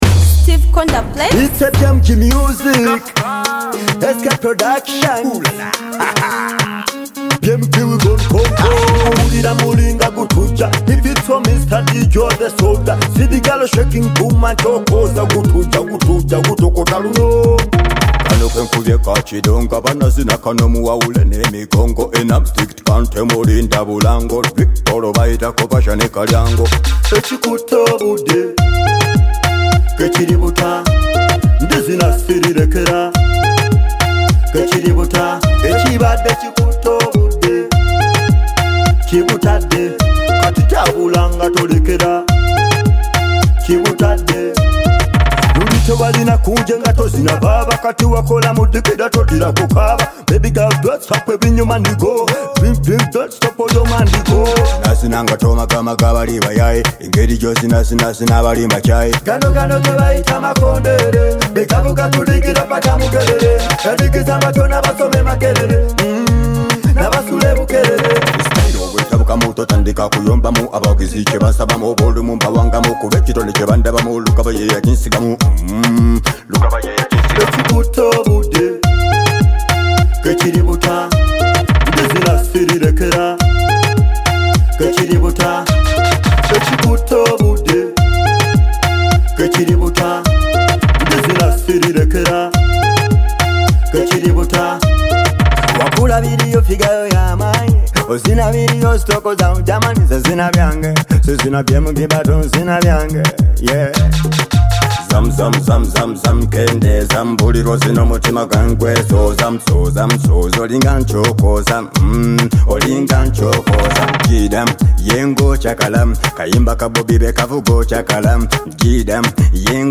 Genre: Ragga